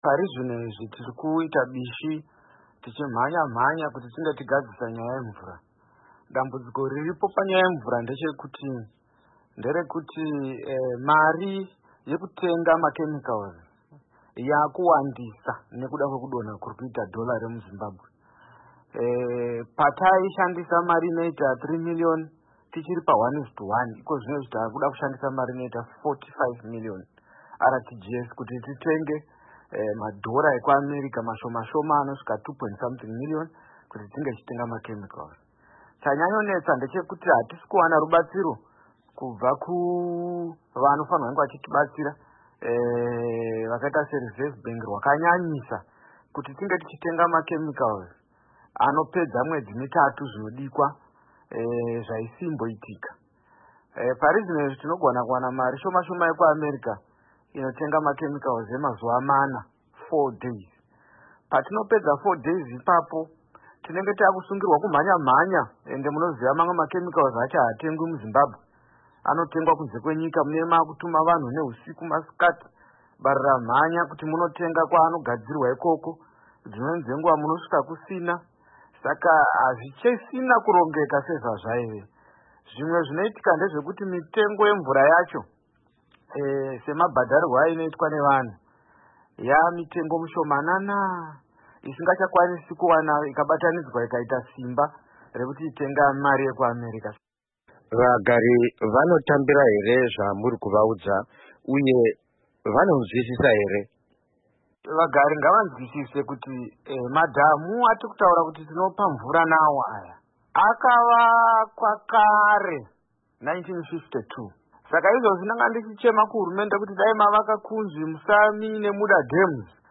Hurukuro naVaHerbert Gomba